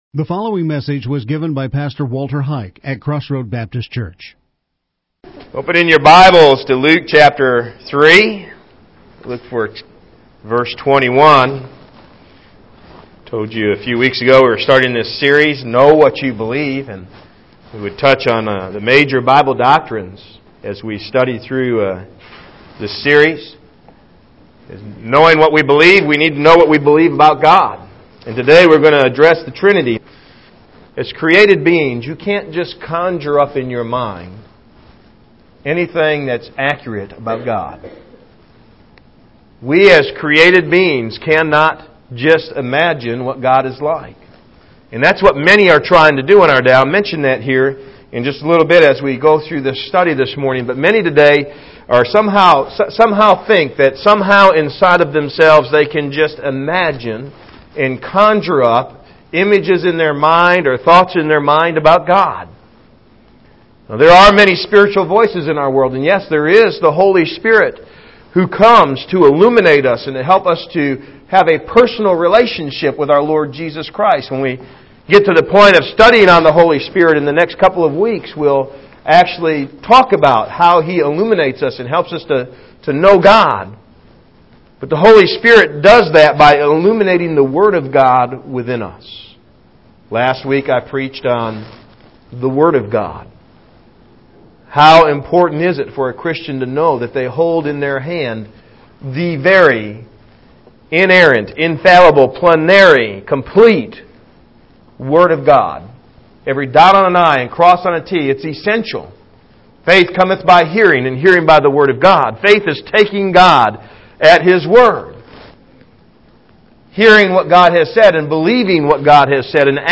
The Trinity Outline and Audio Sermon